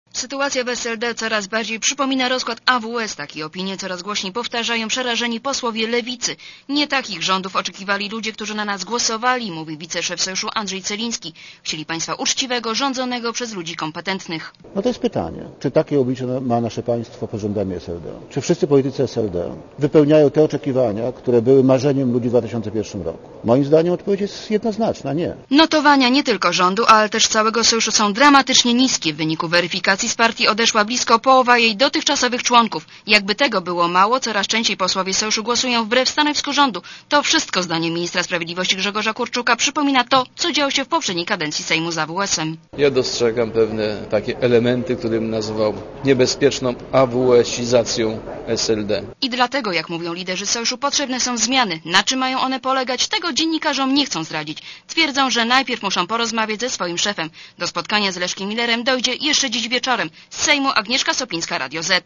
Relacja reportera Radia Zet (244Kb)